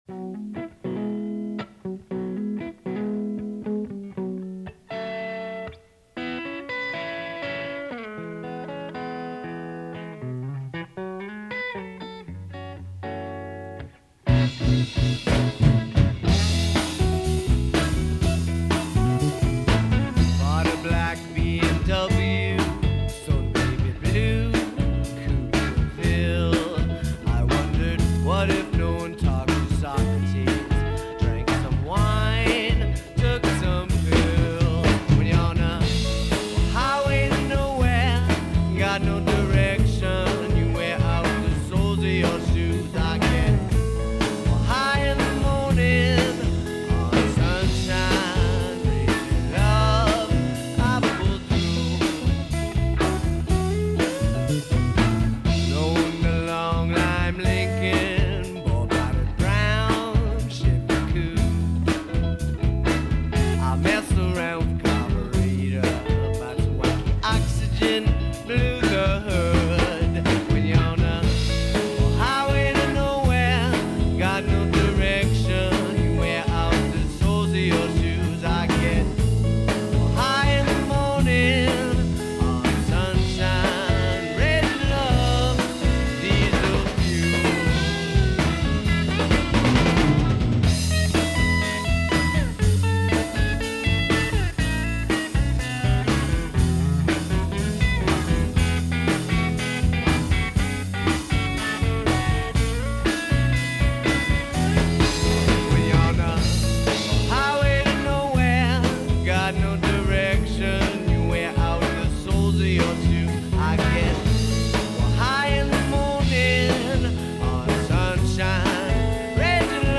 Some of the sound quality is pretty rough.